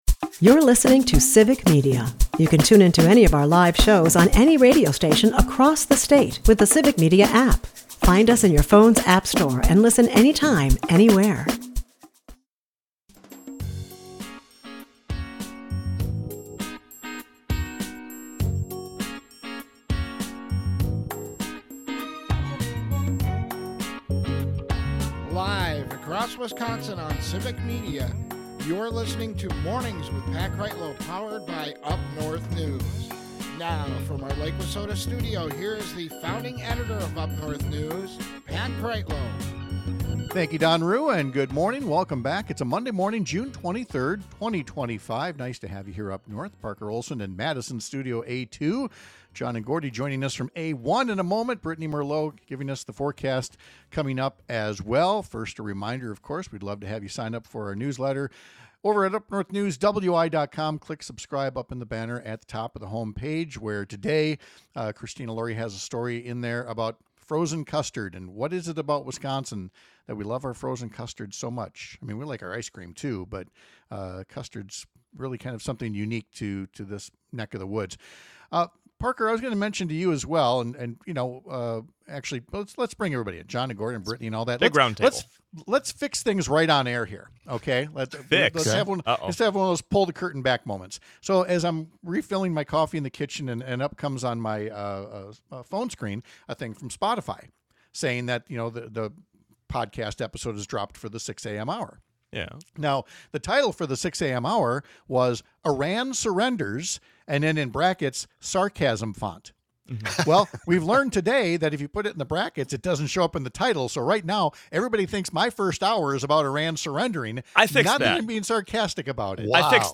We’ll talk to state Rep. Francesca Hong about the ways her far-right colleagues are behaving as if they’d already accomplished their goal of one-party rule.